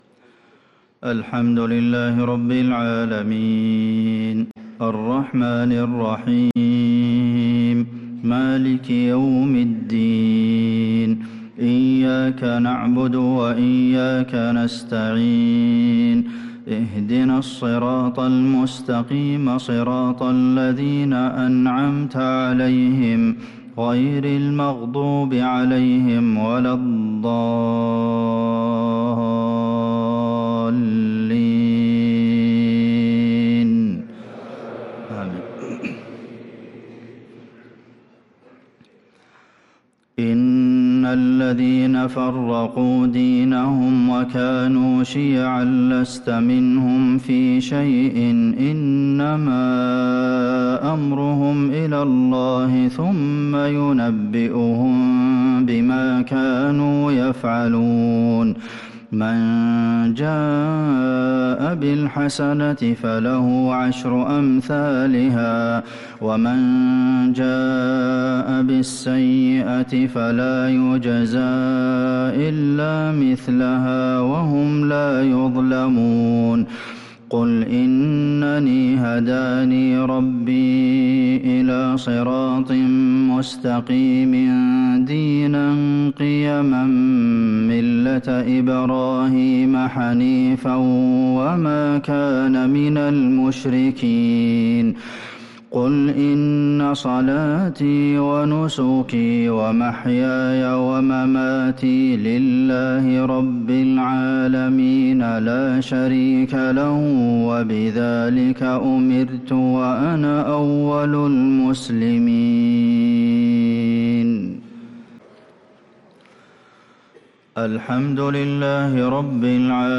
صلاة العشاء ٩-٨-١٤٤٦هـ | خواتيم سورة الأنعام 159-165 | Isha prayer from Surah al-An`am | 8-2-2025 > 1446 🕌 > الفروض - تلاوات الحرمين